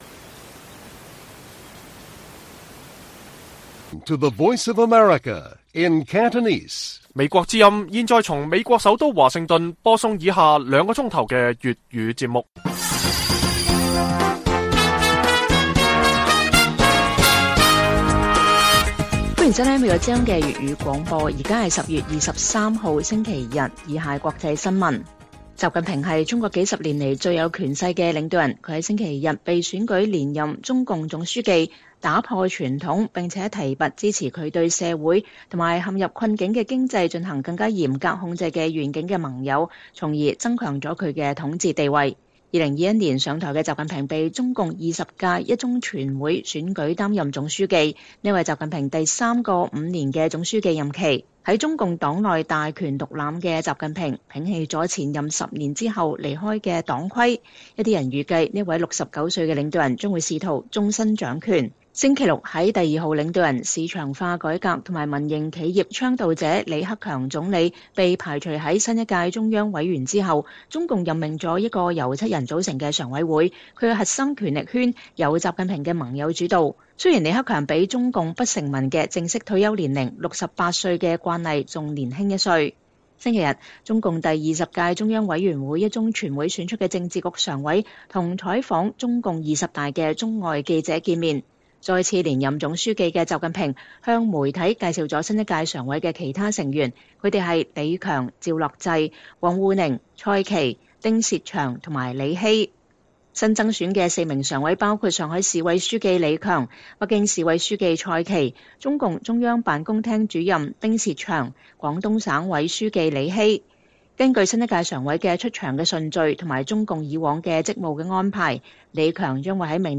粵語新聞 晚上9-10點: 習近平再連任中共總書記 新一屆常委充斥著習的擁護者